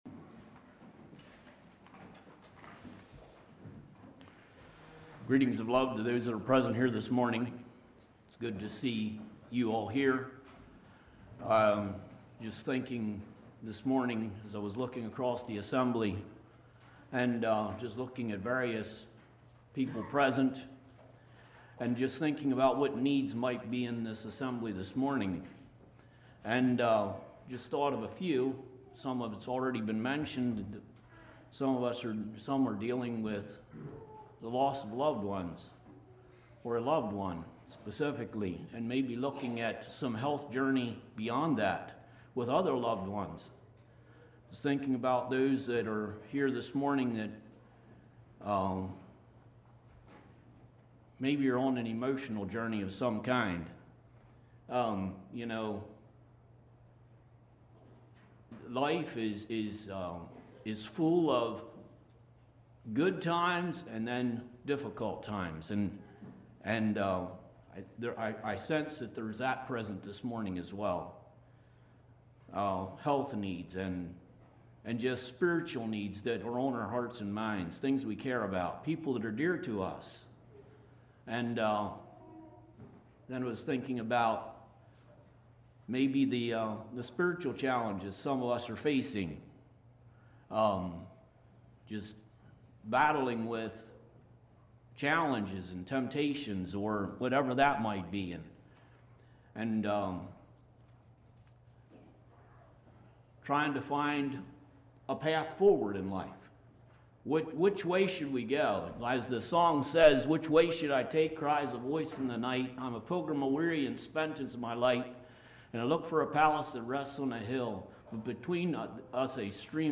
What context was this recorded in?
Congregation: Mount Joy